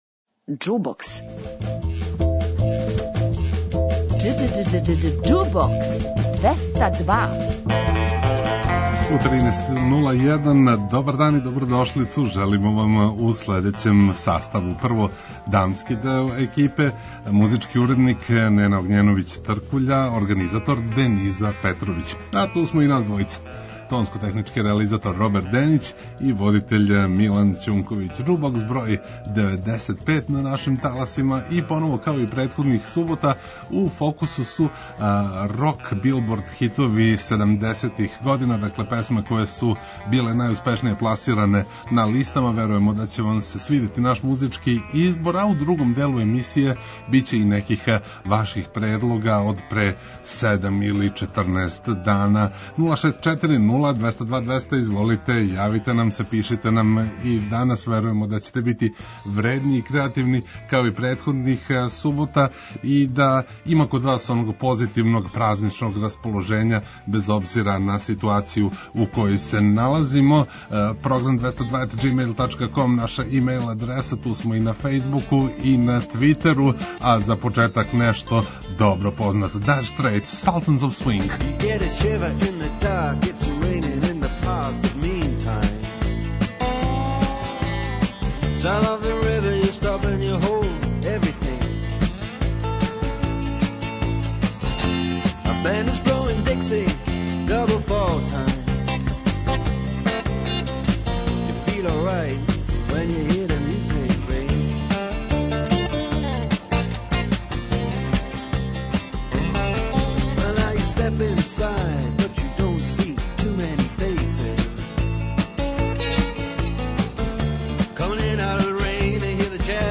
Хитови са Билбордове листе седамдесетих година чиниће и ове суботе окосницу нашег трочасовног музичког времеплова, а усвојили смо и неке од ваших предлога који су пристигли минулих субота.